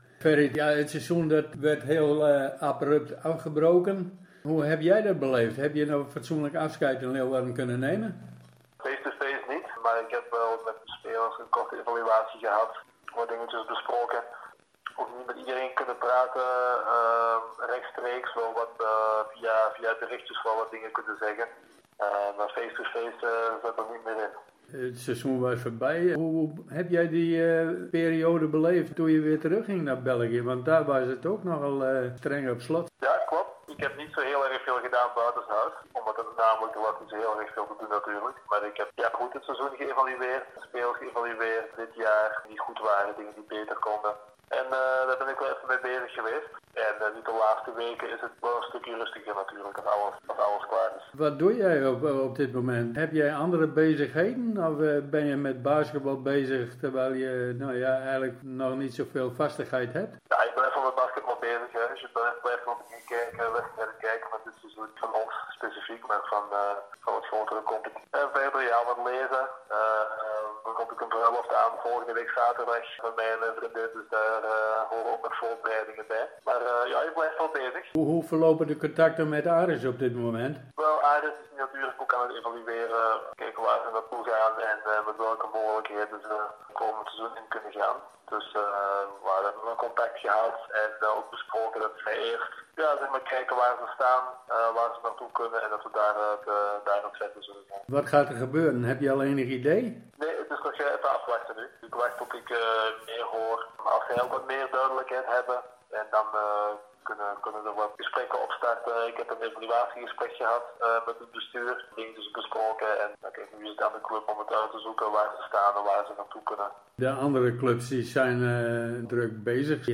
Underweis - Interviews - Sport - Onderweg